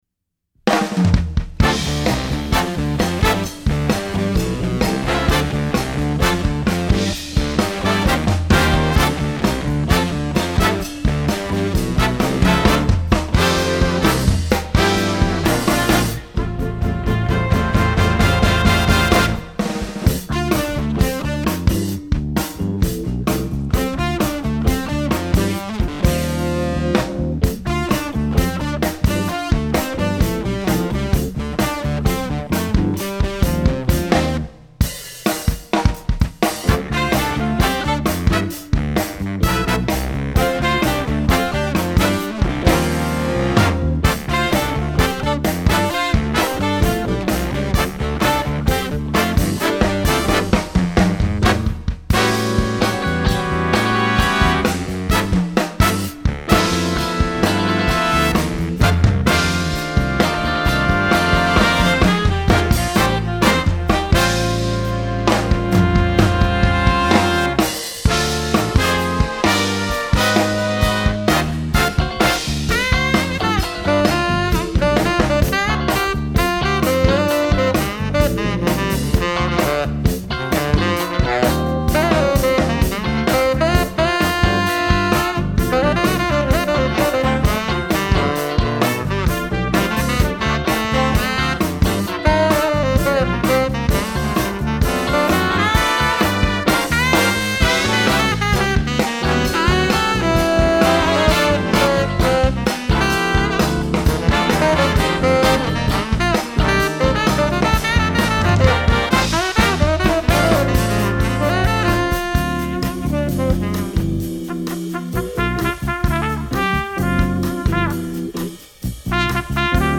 A funky rock groove played around 130 BPM.